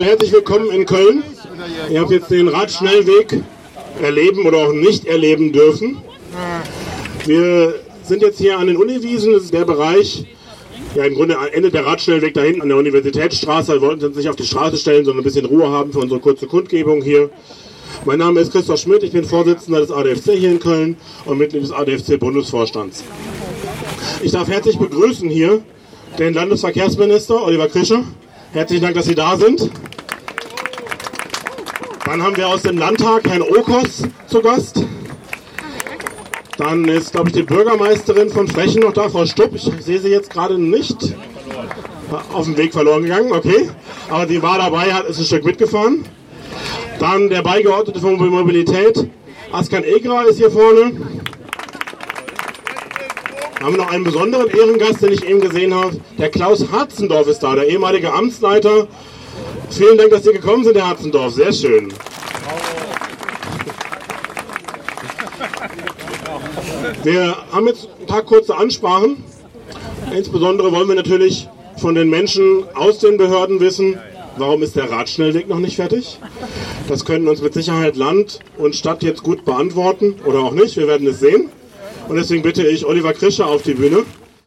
Kapitel 4: Abschlusskundgebung